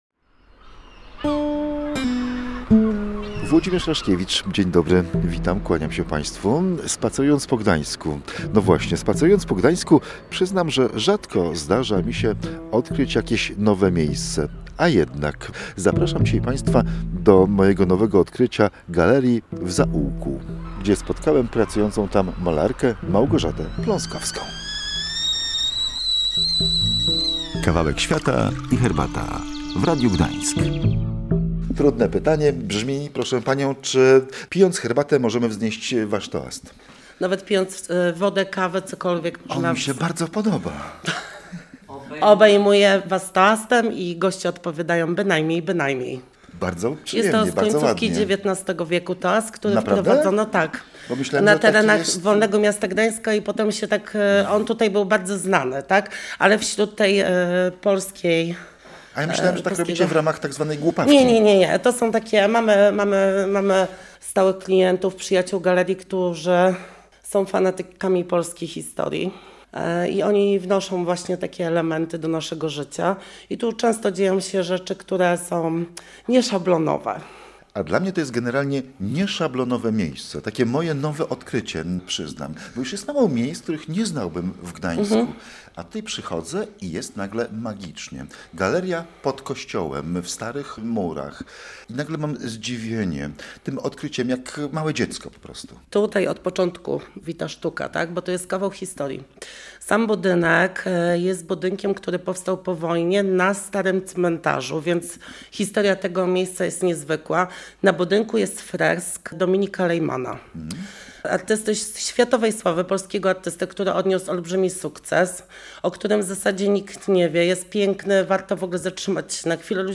To był przyczynek do rozmowy o tym, co dzieje się w galerii na co dzień i kto kupuje w Polsce dzieła sztuki.